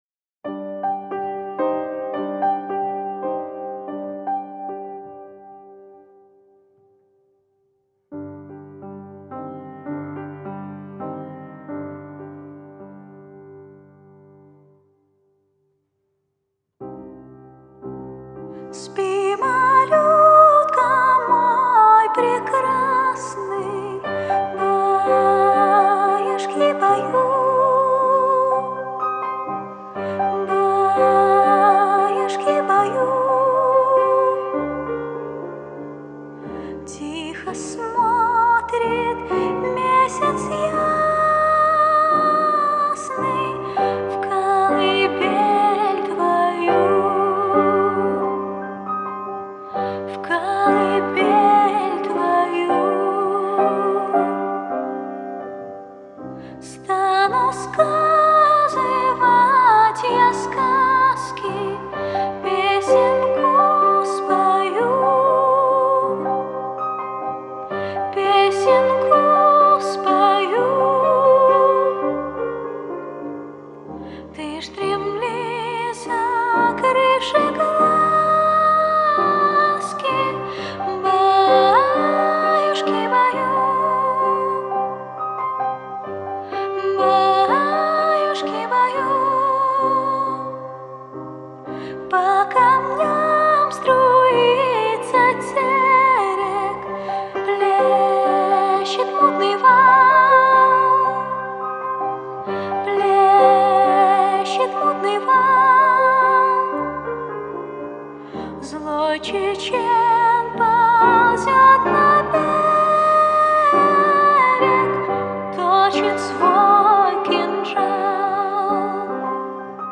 Успокоительная программа – колыбельные песни:
01.-Kazachya-kolyibelnaya-pesnya.mp3